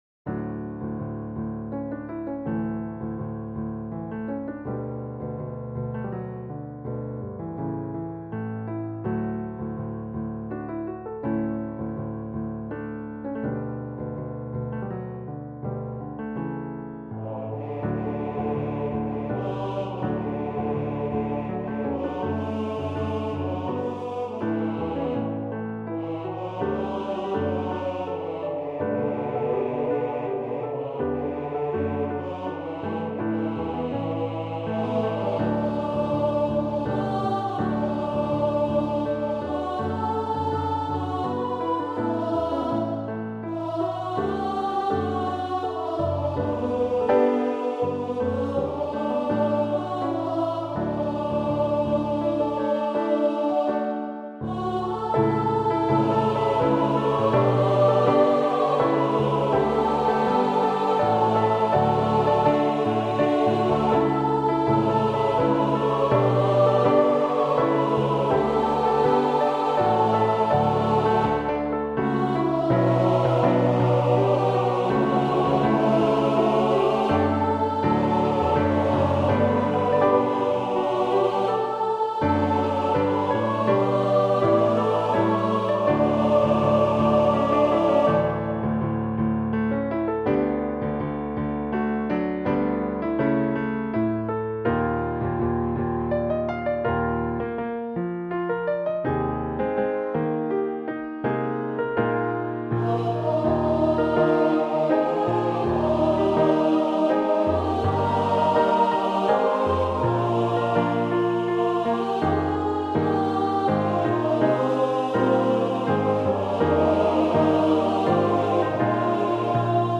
choral setting